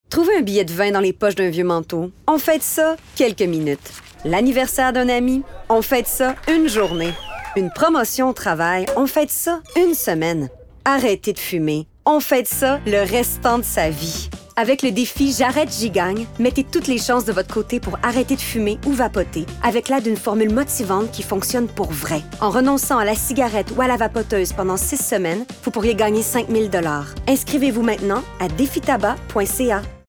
message_radio_djjg_2025.mp3